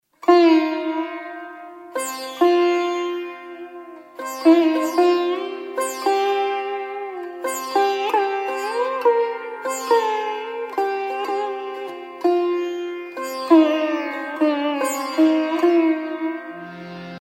زنگ موبایل بی کلام ملایم و زیبا(با ملودی پاکستانی)